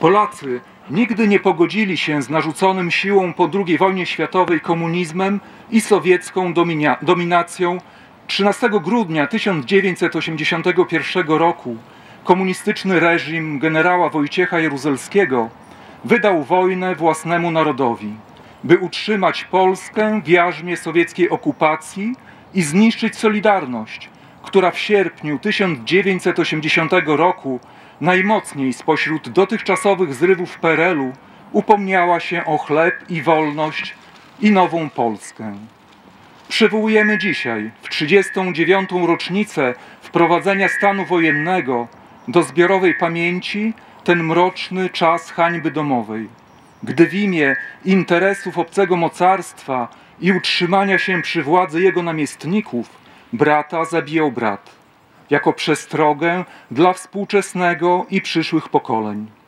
Obchody 39. rocznicy wprowadzenia stanu wojennego w Polsce odbyły się w niedzielę (13.12.20) w Suwałkach.
Jacek Juszkiewicz, przewodniczący klubu Prawo i Sprawiedliwość w suwalskiej Radzie Miejskiej odczytał list Jarosława Zielińskiego, posła Prawa i Sprawiedliwości.